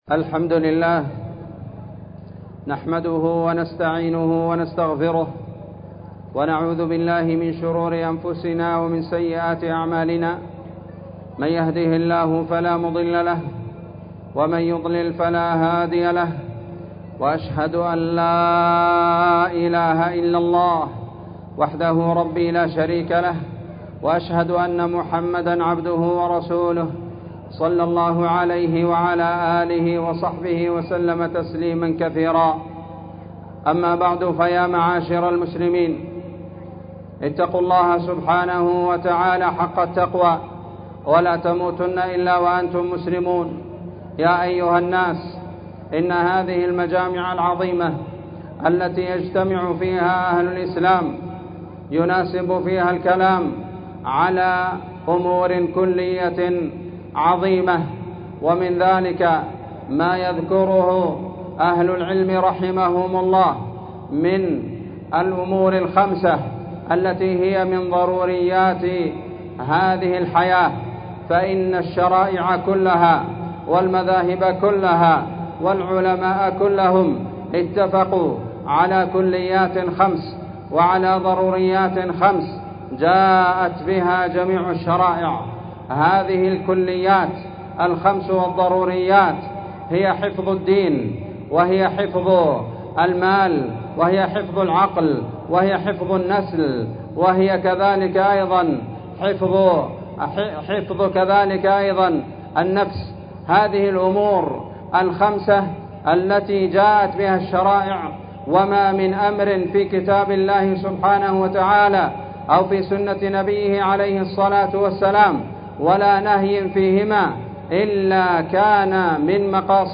خطبة عيد الأضحى المبارك بعنوان حفظ الضروريات الخمس 10 ذو الحجة 1444
مصلى حول الشجرة- النسيرية- تعز